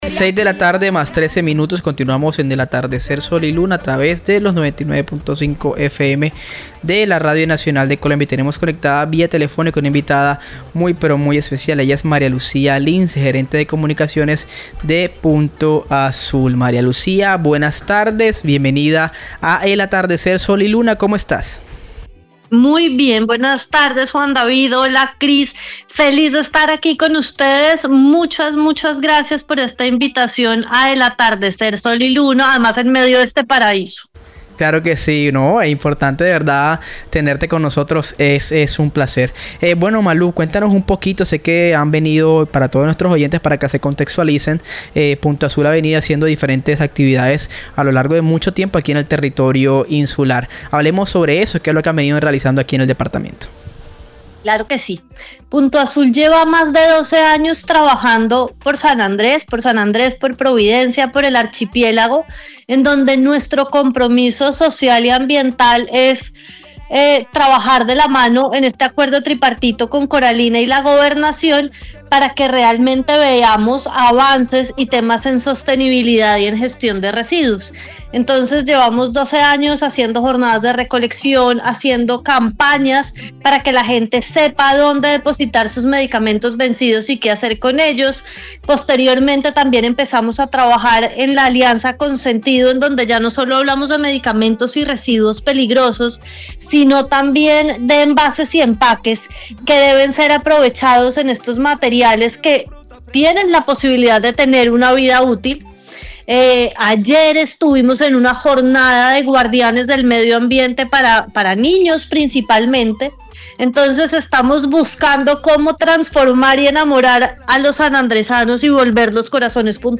Nota entrevista de Radio Nacional | Punto Azul